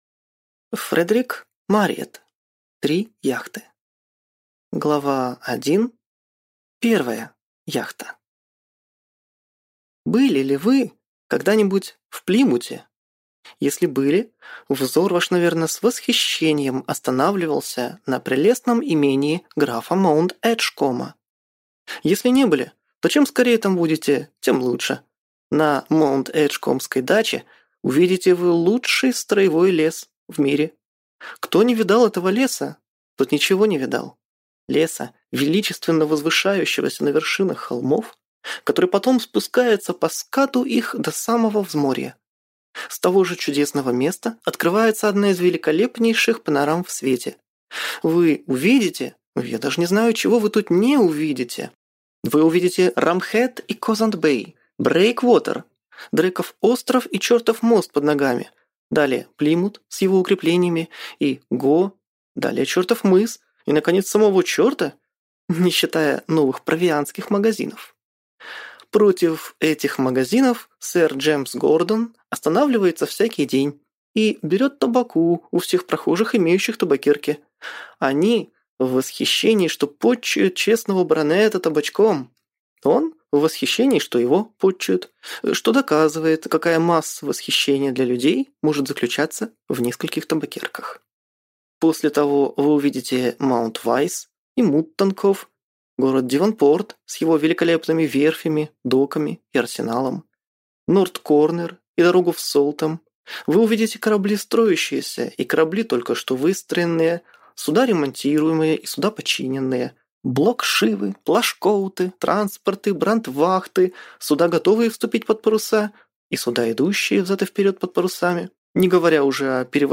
Аудиокнига Три яхты | Библиотека аудиокниг
Прослушать и бесплатно скачать фрагмент аудиокниги